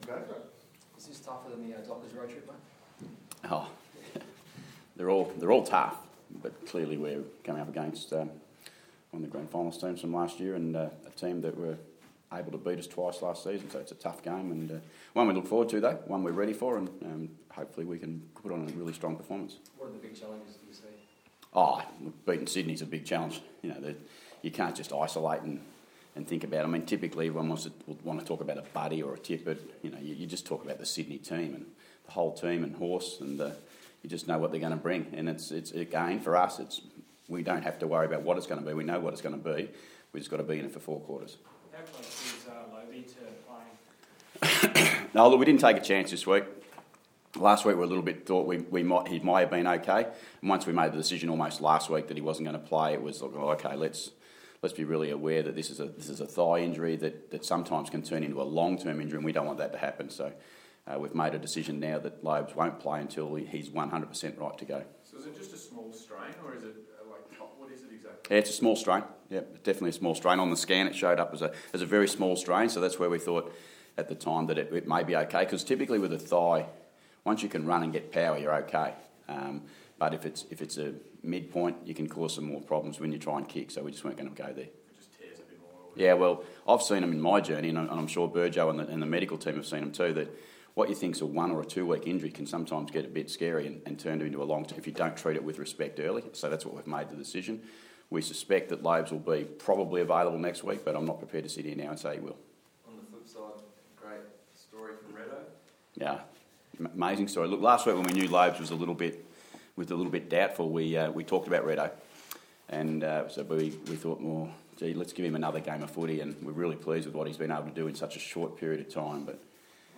Ken Hinkley press conference - Friday 10 April, 2015